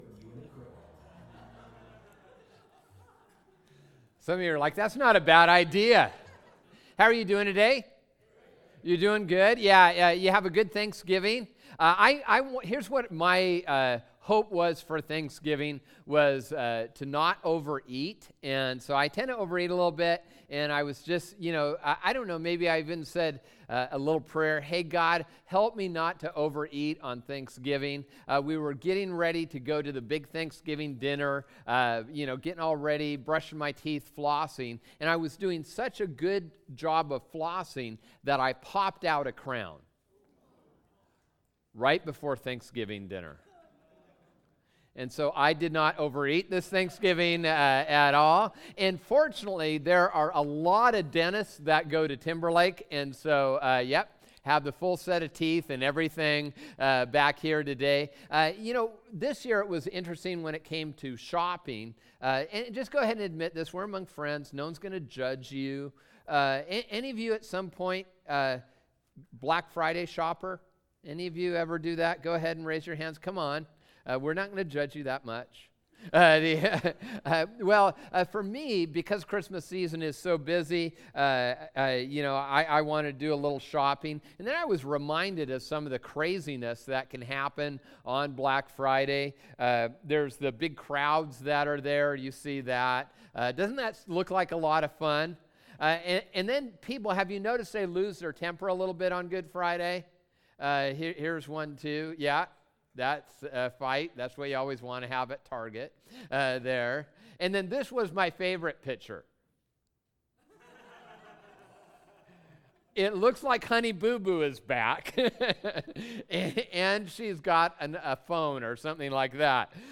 sermon_final.mp3